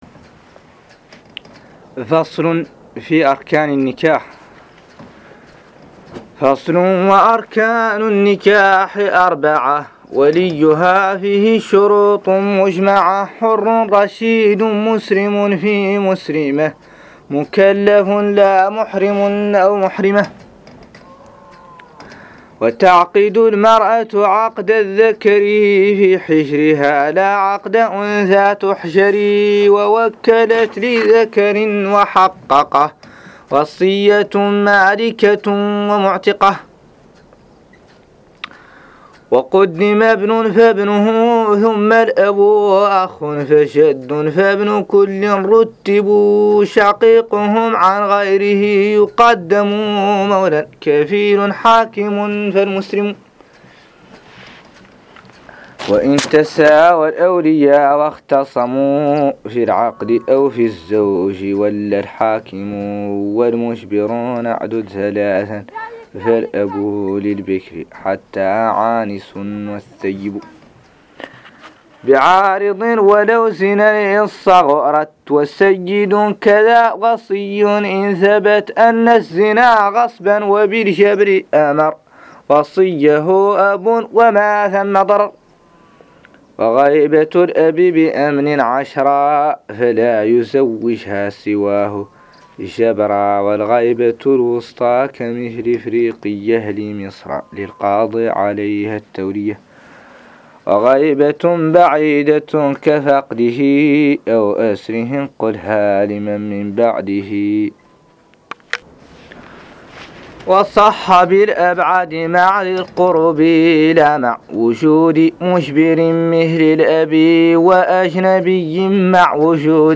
قراءة منهاج السالك 04